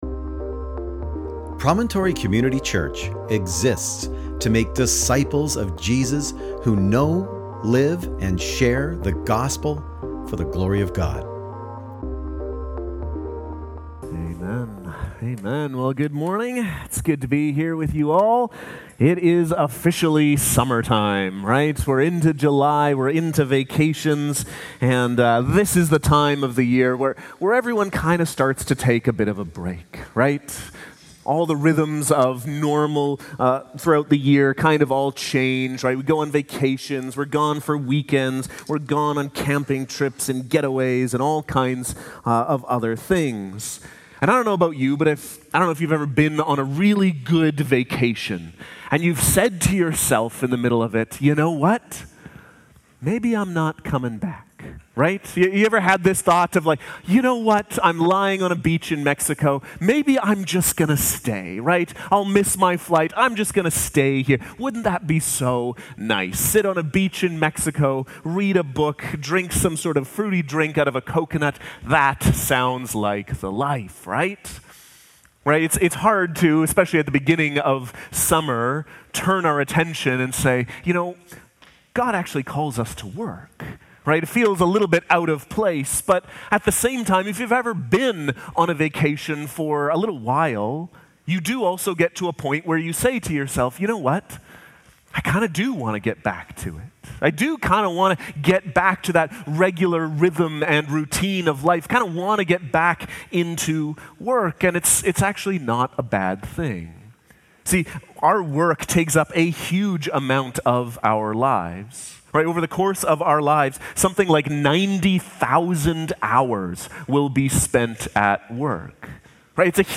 July-6th-Sermon.mp3